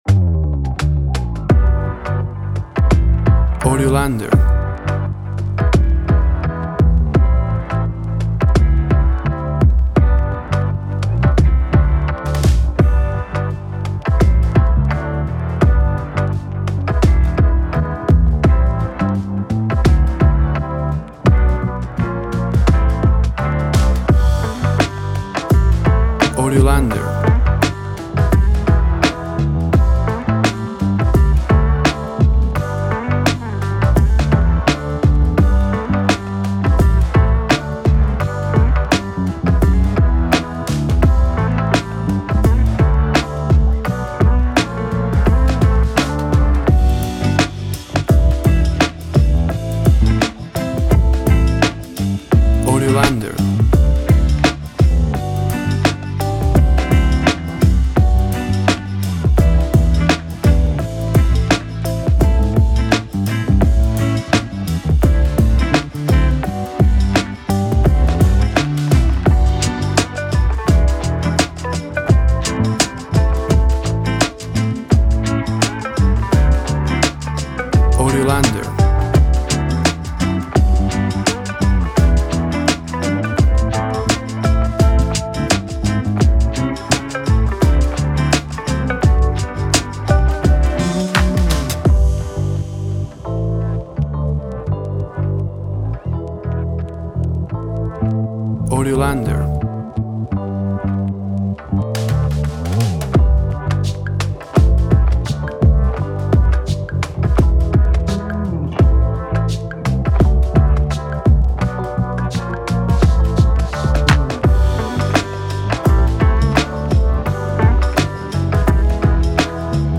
LoFi chill hop track
WAV Sample Rate 24-Bit Stereo, 44.1 kHz
Tempo (BPM) 85